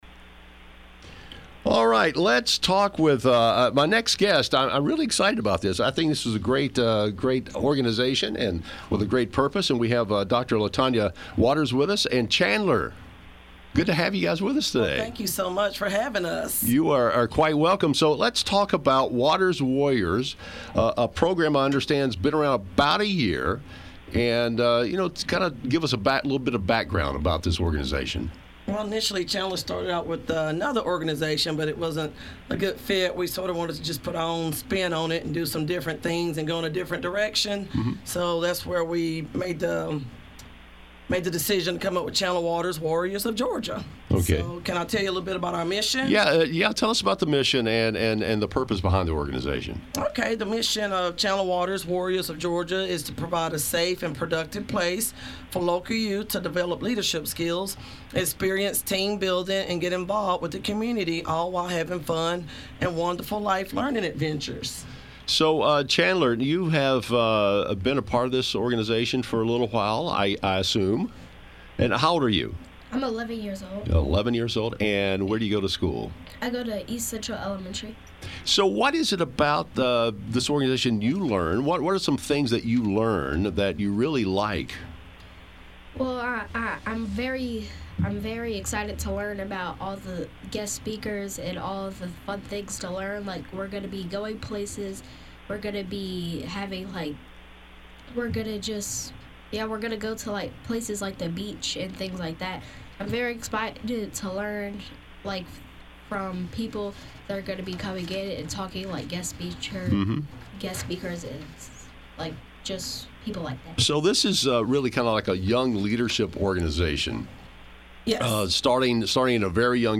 Radio Interview WRGA August 14, 2023